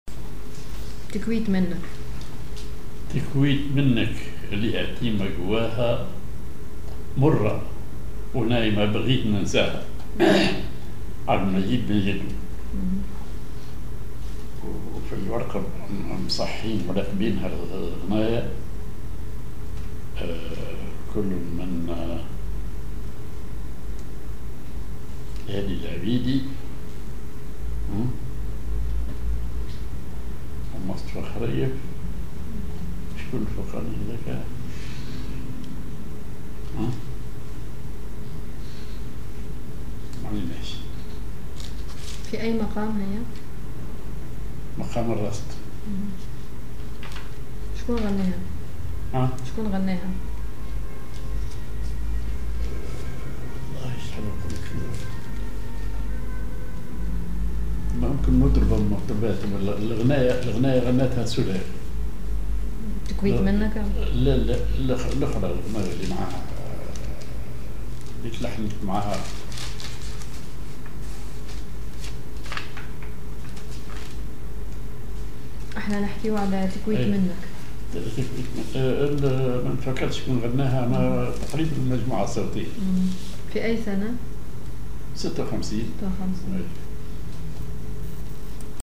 Maqam ar راست
genre أغنية